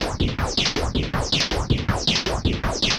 RI_RhythNoise_80-01.wav